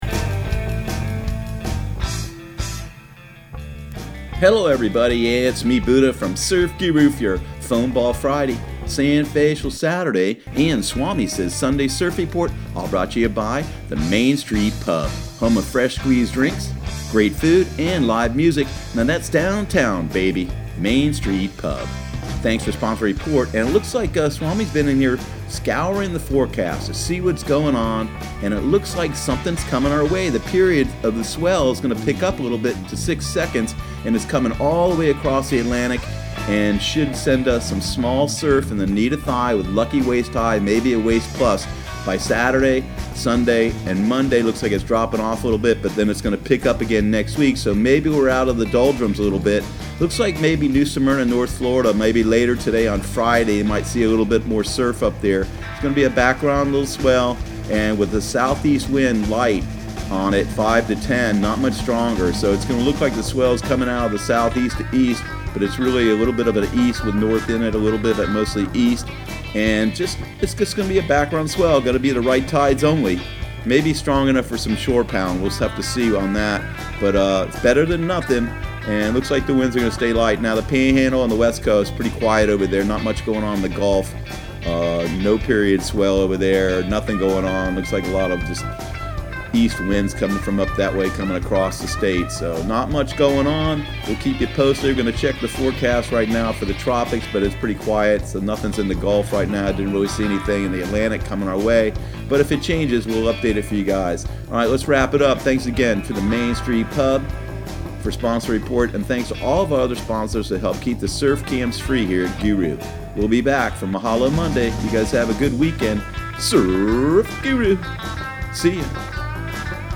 Surf Guru Surf Report and Forecast 07/26/2019 Audio surf report and surf forecast on July 26 for Central Florida and the Southeast.